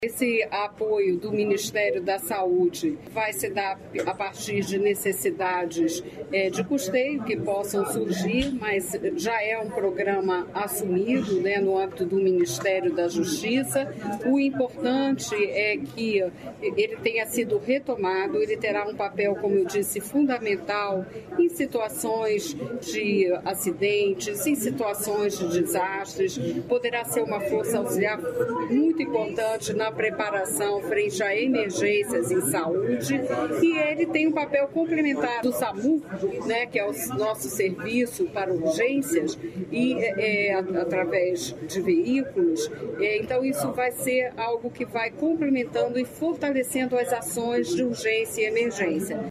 Sonora da Ministra da Saúde, Nísia Trindade, sobre novo helicóptero da PRF que ficará à disposição para resgates aeromédicos na RMC e Litoral do Paraná